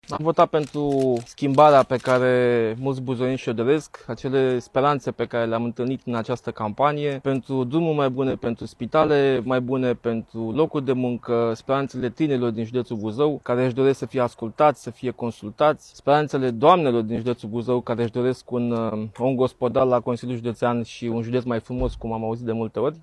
a declarat, la ieşirea de la urne, că a votat pentru speranţele pe care le-a adunat de la buzoieni şi pentru dezvoltarea serviciilor publice şi infrastructurii.